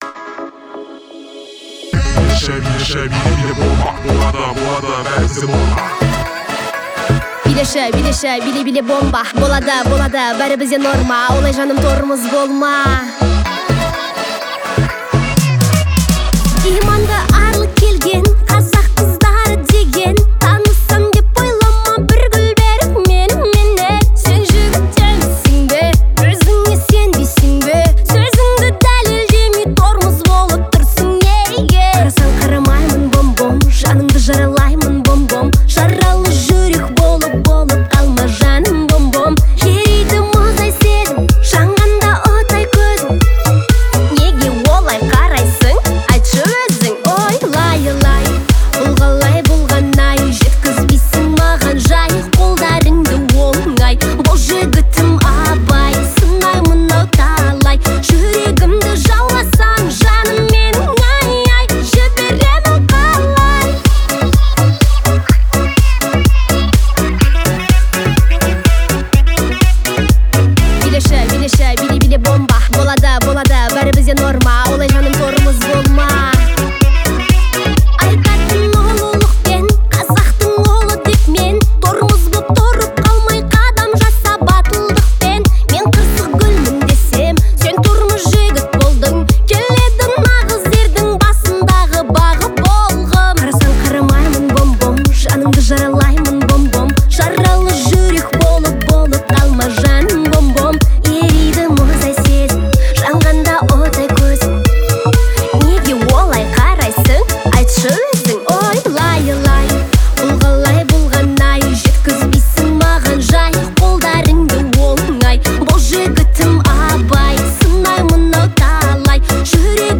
Музыка сочетает запоминающийся бит с ярким вокалом
создавая динамичное звучание.